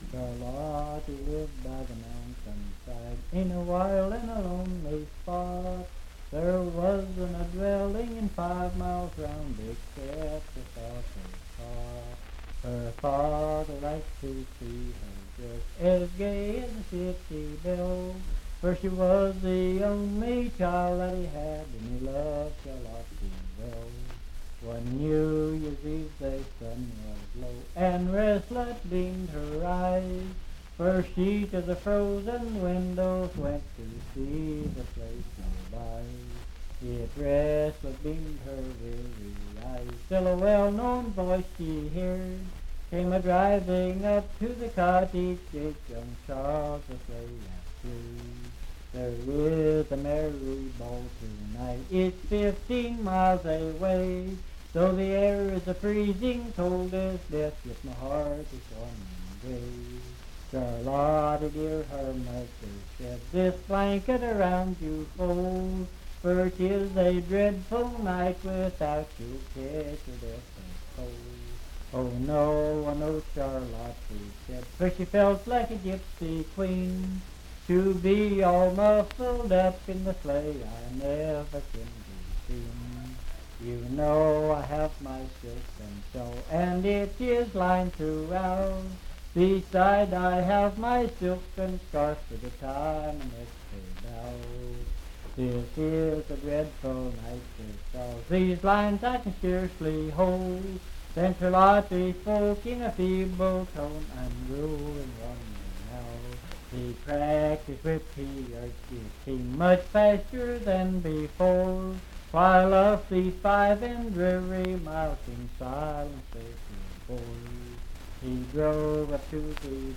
Unaccompanied vocal music
in Riverton, W.V.
Voice (sung)